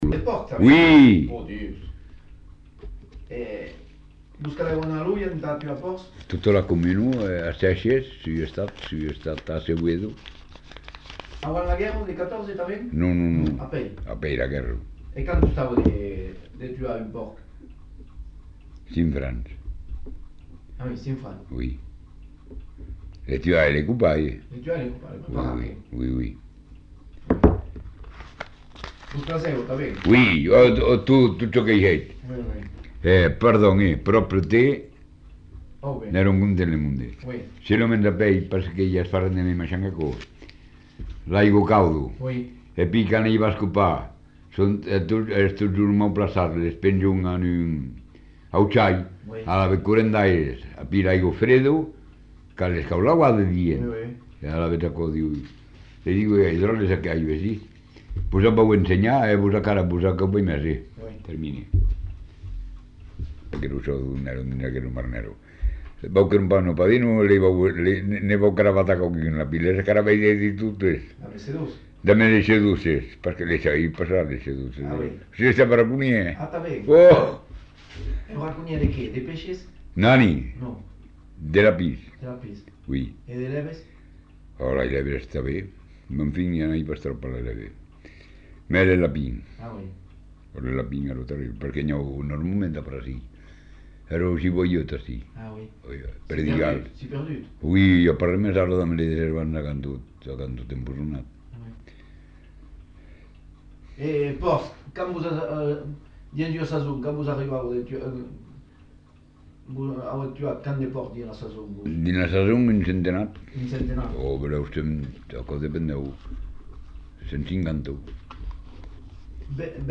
Genre : récit de vie